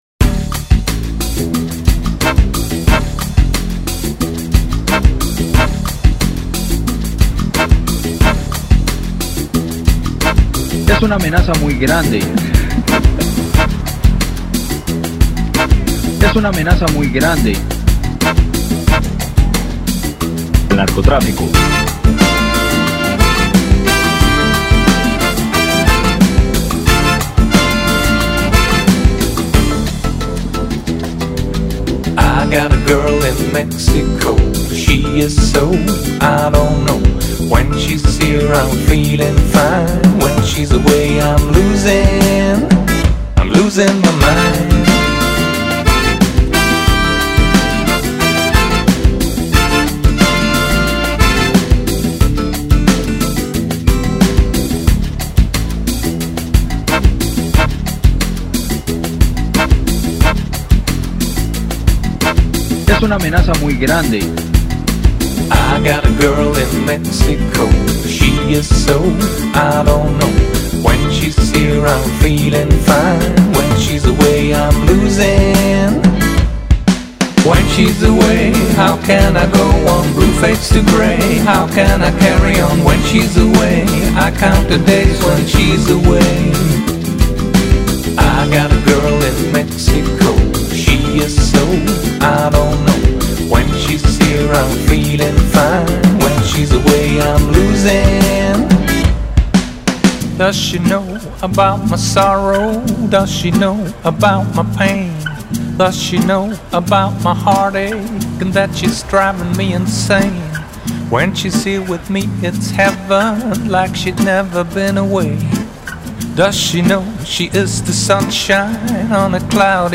Singer-songwriter / pop.